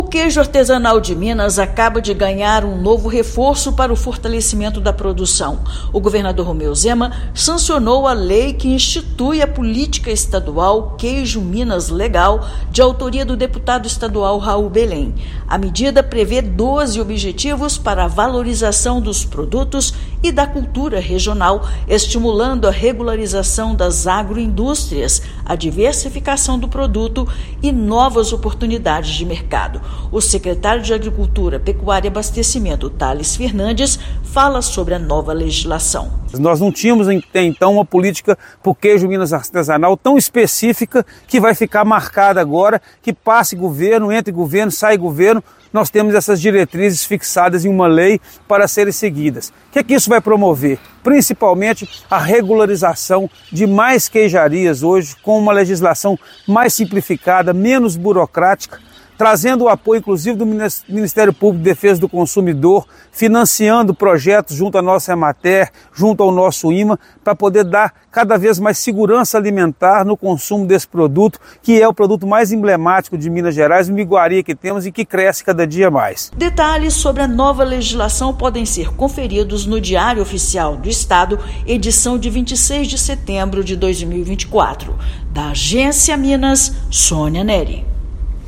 Política Estadual Queijo Minas Legal, sancionada nesta semana, traz 12 diretrizes para o fortalecimento do setor. Ouça matéria de rádio.